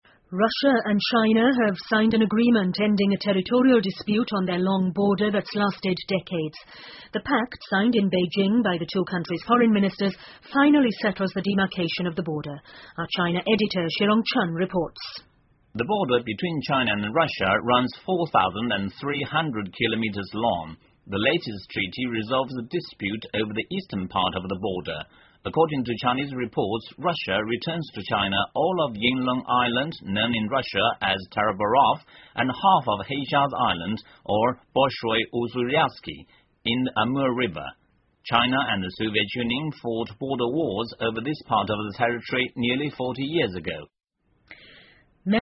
英国新闻听力 中俄签署议定书 全线勘定两国边界 听力文件下载—在线英语听力室